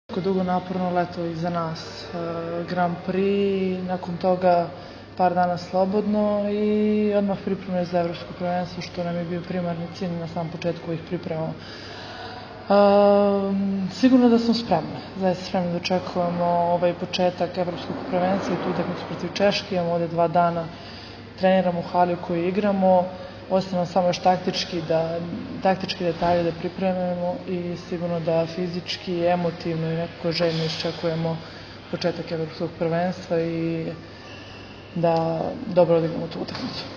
IZJAVA TIJANE MALEŠEVIĆ